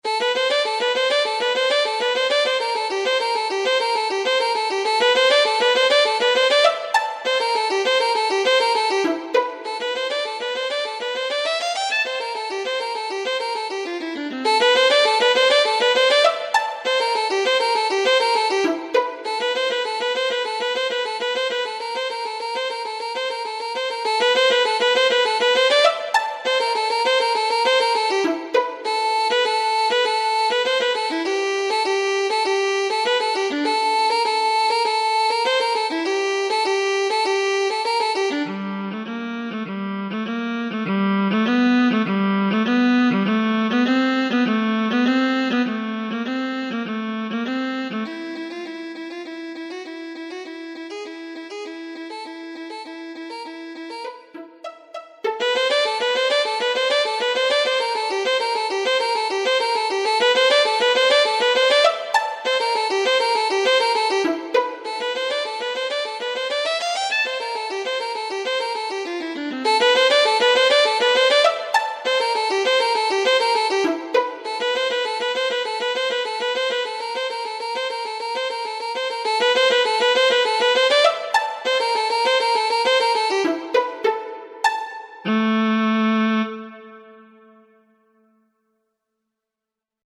Scored for: Solo violin